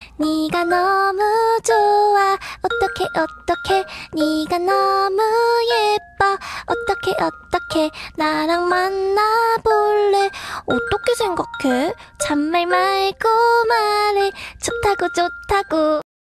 Kategori: Nada dering
dengan suara wanita ini